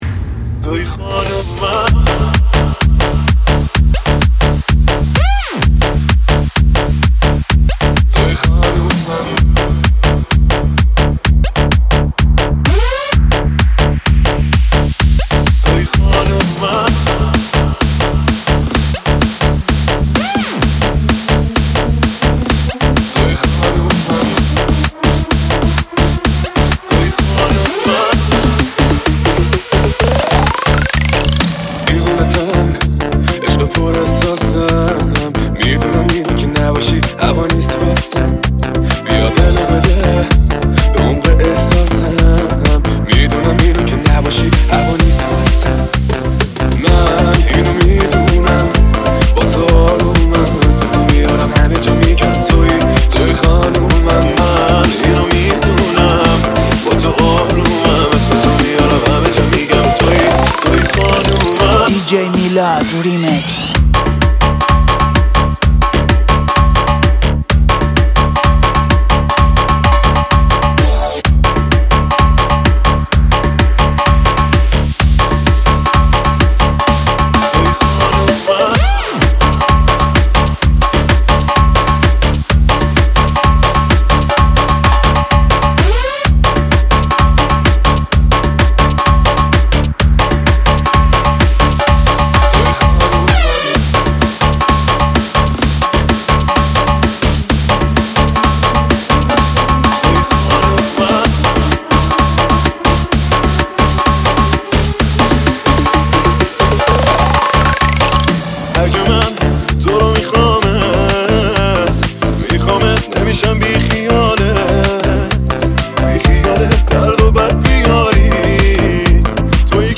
دانلود آهنگ شاد با کیفیت ۱۲۸ MP3 ۳ MB